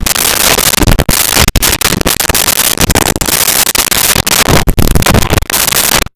Pay Phone Sequence
Pay Phone Sequence.wav